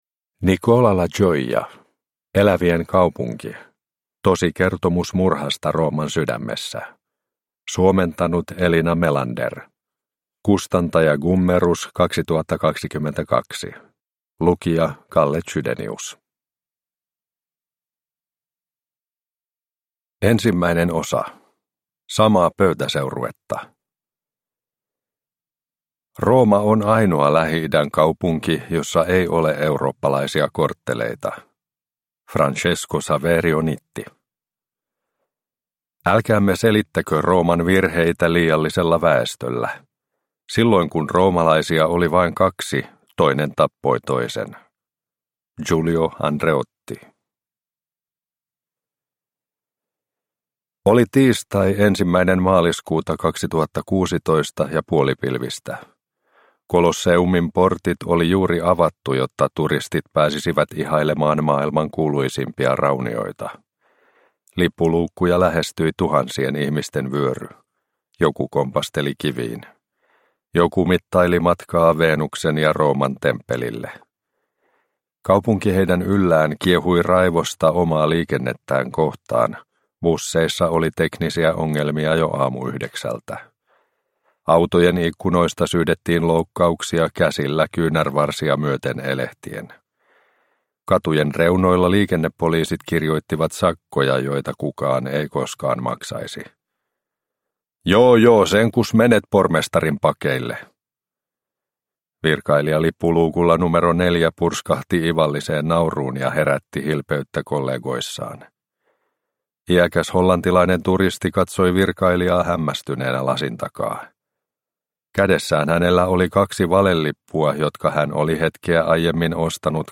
Elävien kaupunki – Ljudbok – Laddas ner